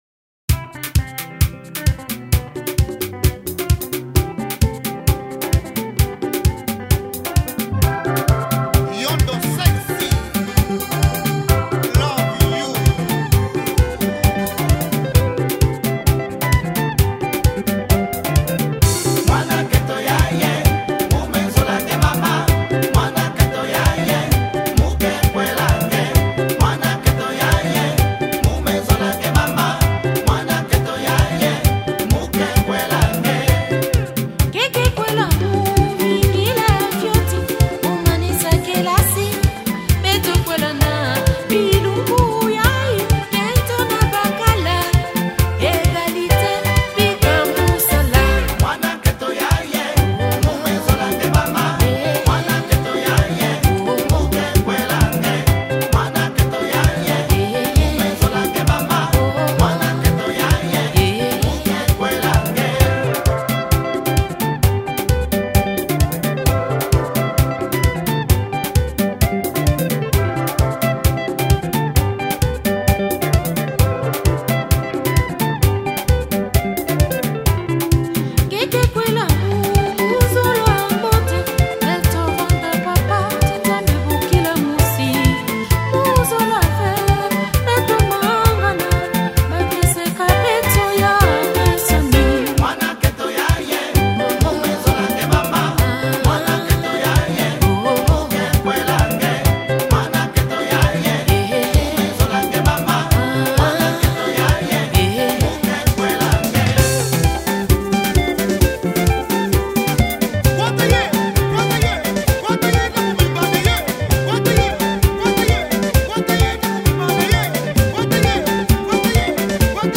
Rhumba